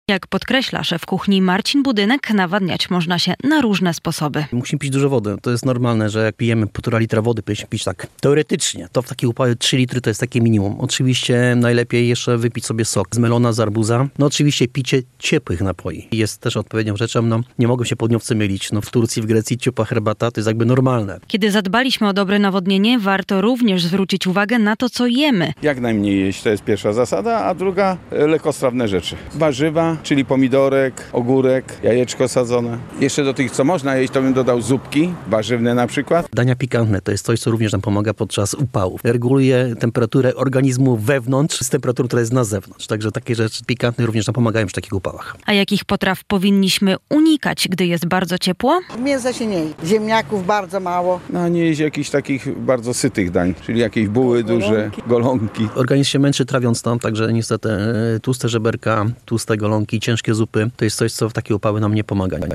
Dieta i nawodnienie w czasie upałów - co wybrać? - relacja